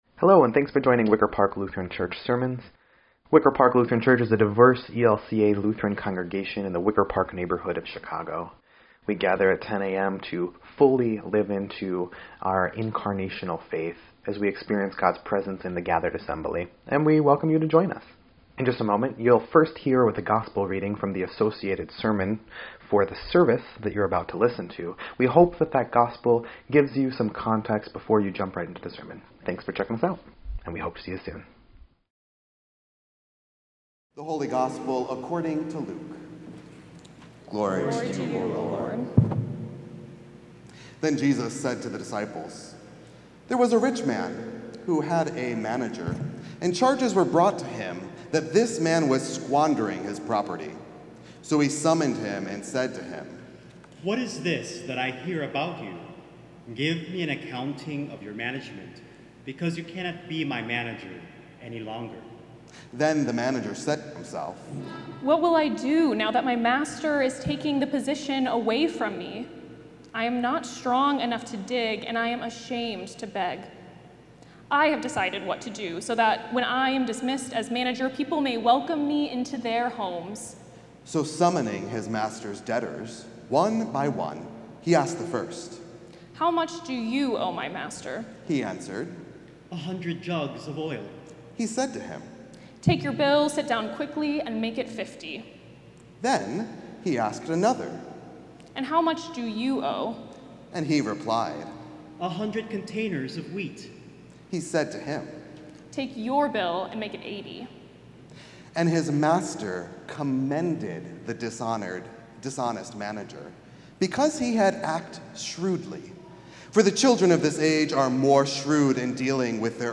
9.21.25-Sermon_EDIT.mp3